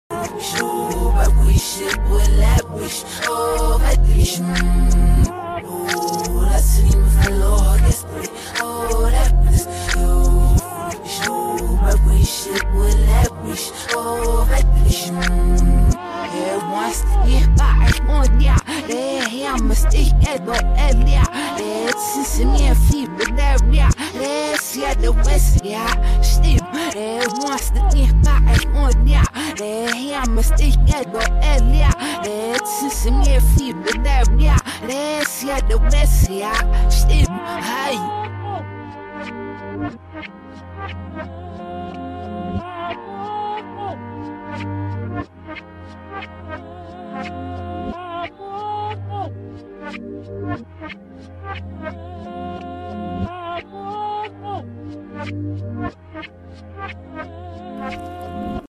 in reverse